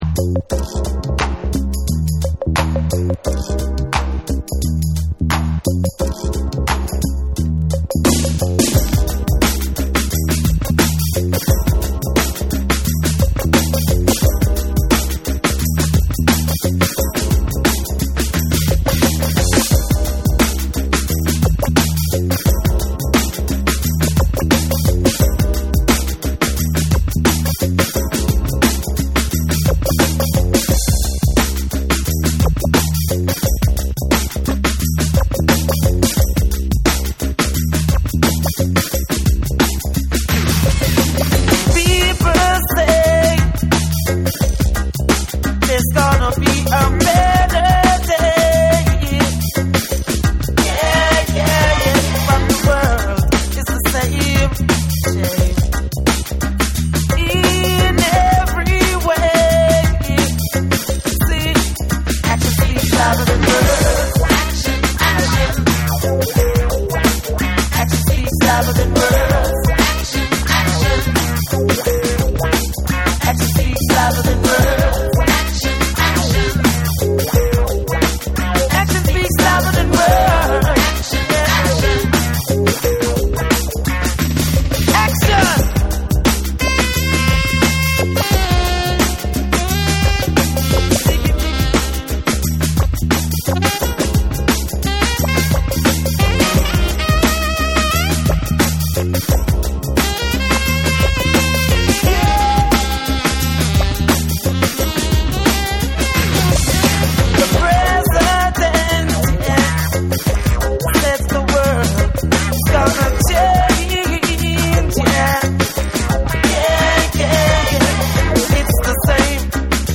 リズムを強調させヴォーカルを飛ばし、強烈なエレクトリック・ダブに仕立てた
BREAKBEATS / REGGAE & DUB